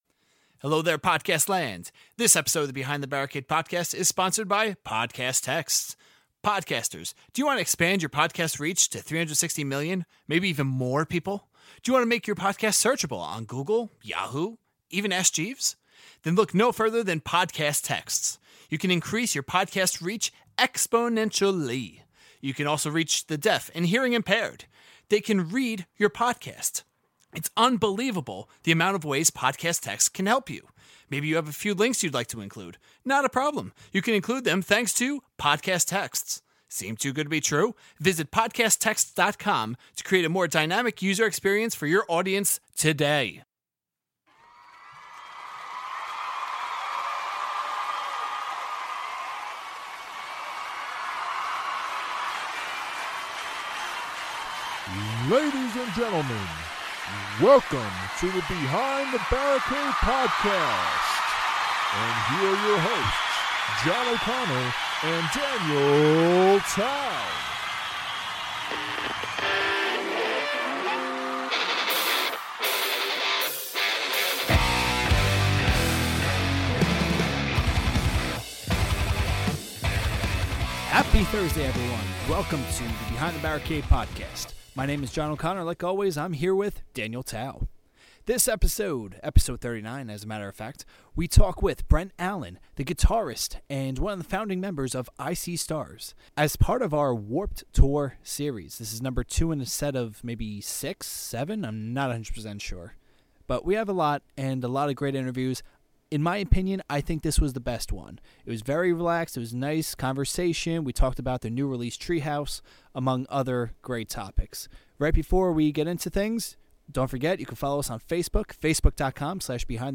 Episode 39 is the second of our Warped Tour 2016 series!
This was one laid back and chill interview talking about their new album Treehouse, how they create music, Snapchat and more.